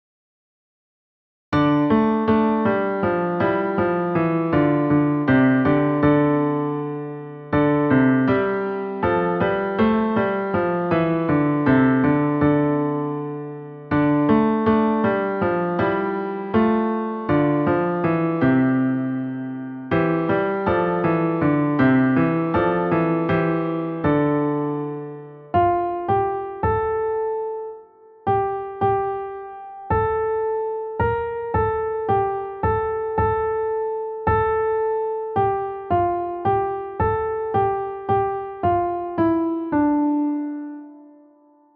• Music Type: Choral
• Voicing: SATB
• Accompaniment: a cappella